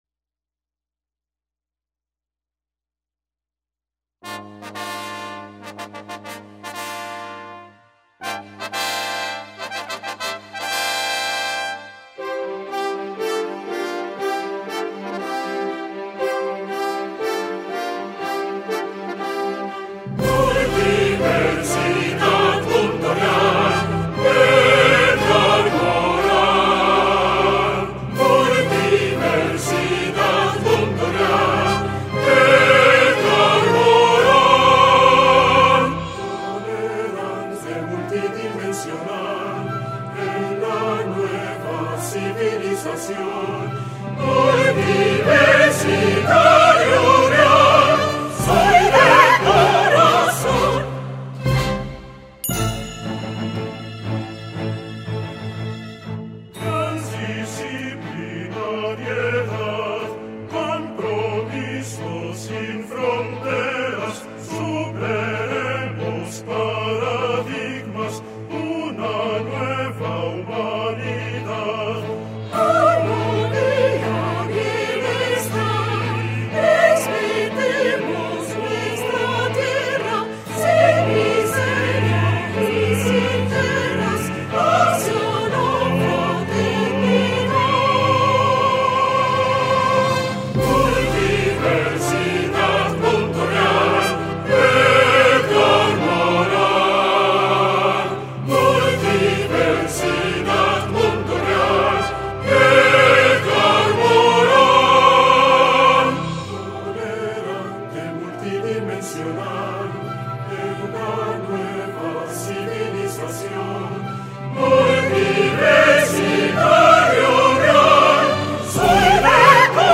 Fanfarria orquestal
Soprano